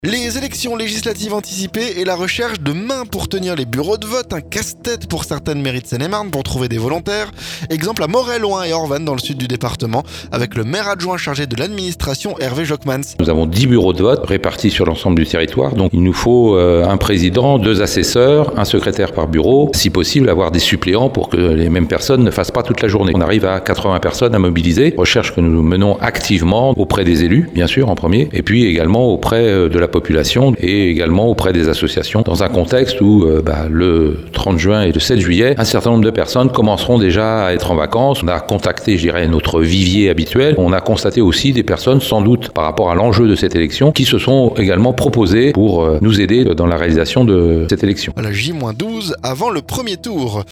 Casse-tête pour certaines mairies pour trouver des volontaires... Exemple à Moret Loing et Orvanne, dans le sud Seine-et-Marne, avec le maire adjoint chargé de l'administration Hervé Jochmans.
Reportage